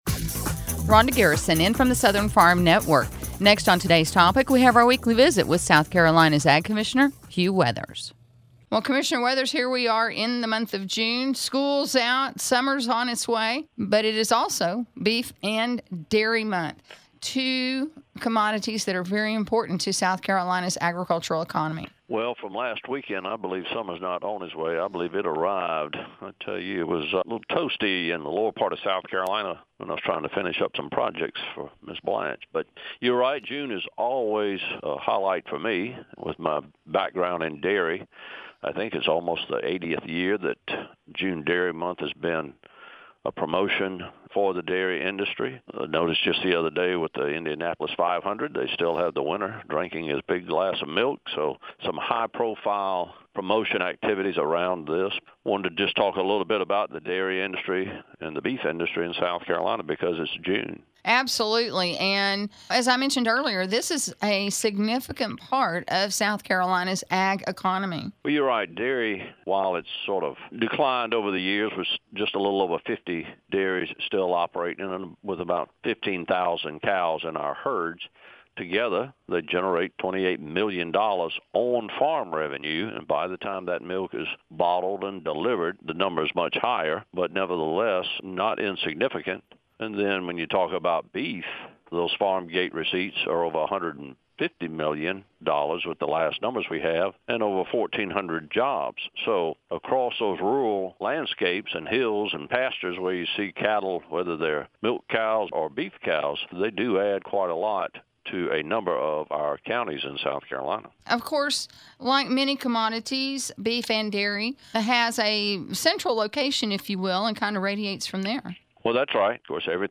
Every week Commissioner Hugh Weathers answers questions about what’s going on with agriculture in our state with The Southern Farm Network.